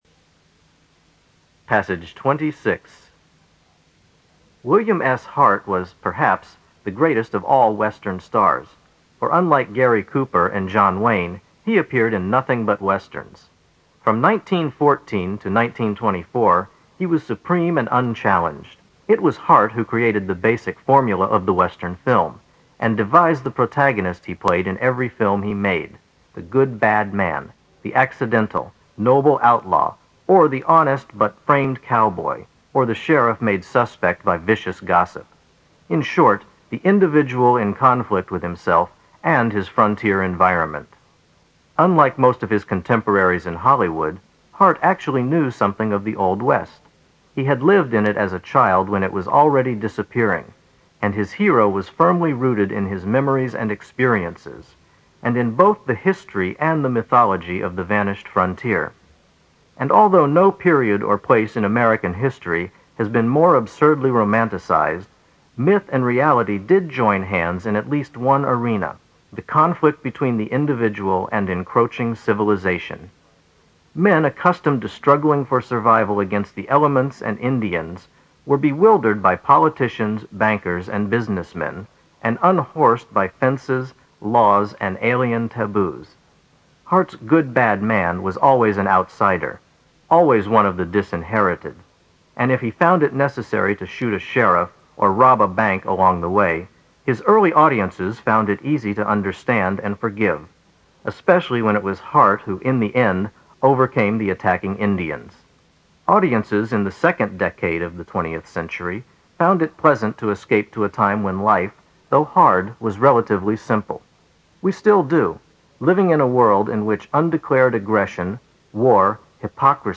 在线英语听力室新概念英语85年上外美音版第四册 第26课的听力文件下载,新概念英语,85年上外美音版-在线英语听力室